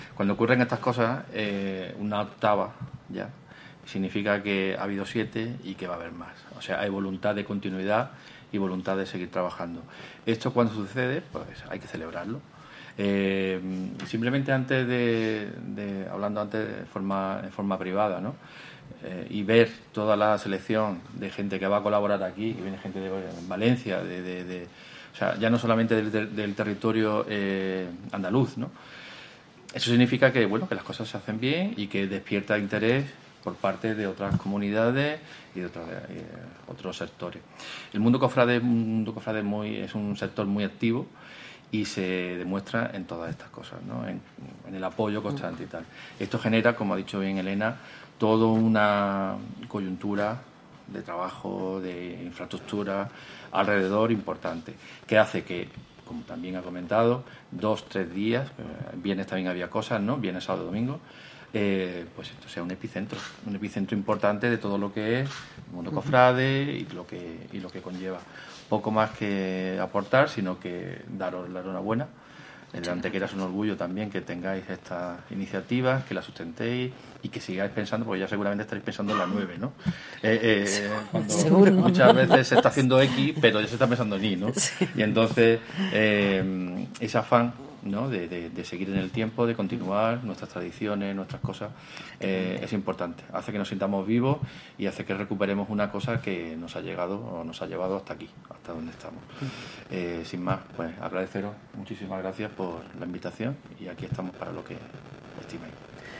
La teniente de alcalde de Tradiciones, Elena Melero, y el concejal delegado de Cultura y Patrimonio Histórico, José Medina Galeote, han asistido en el mediodía de hoy miércoles a la presentación oficial de la VIII Expo de Arte Cofrade que la entidad local autónoma de Bobadilla Estación acogerá del 6 al 8 de octubre, convirtiéndose así en epicentro del mundo cofrade durante ese fin de semana.
Cortes de voz